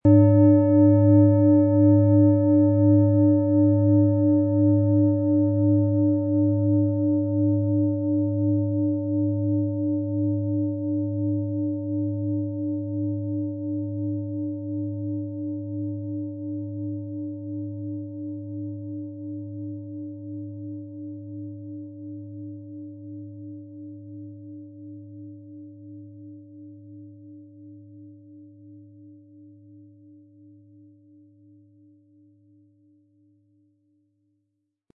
• Mittlerer Ton: Mars
Unter dem Artikel-Bild finden Sie den Original-Klang dieser Schale im Audio-Player - Jetzt reinhören.
PlanetentöneUranus & Mars & Biorhythmus Körper (Höchster Ton)
HerstellungIn Handarbeit getrieben
MaterialBronze